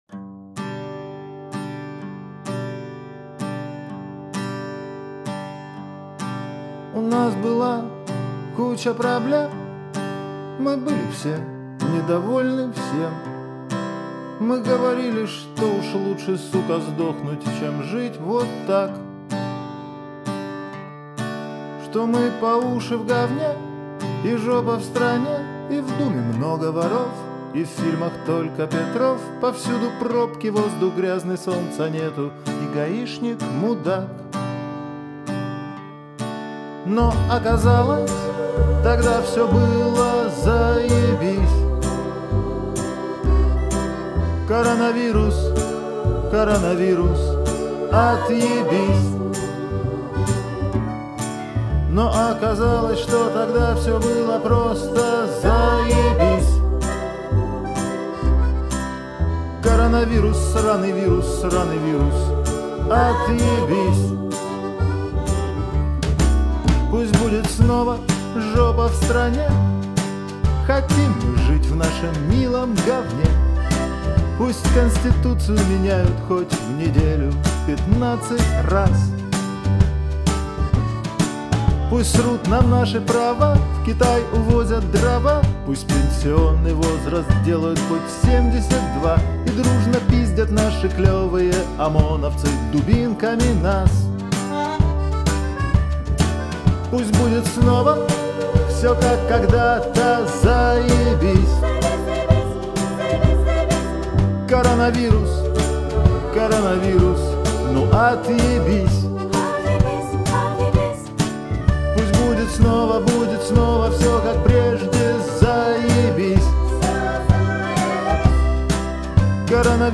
во время самоизоляции
Вместе с большим ансамблем исполнителей.